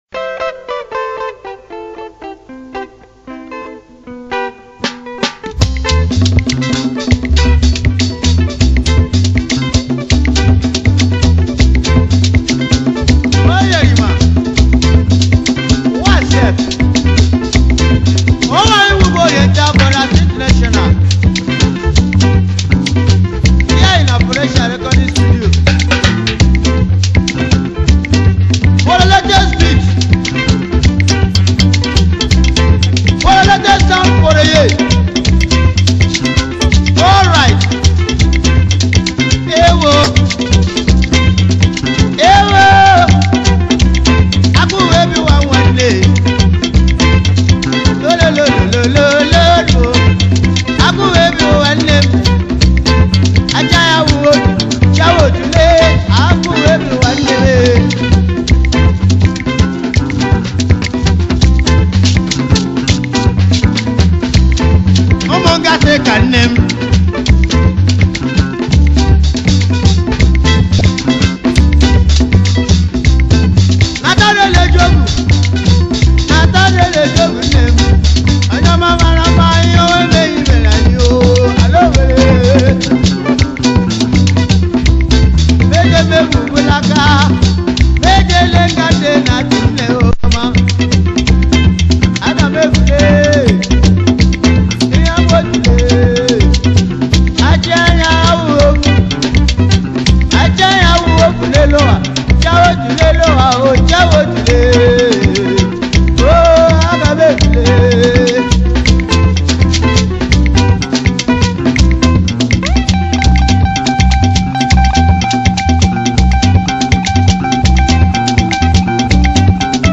February 26, 2025 Publisher 01 Gospel 0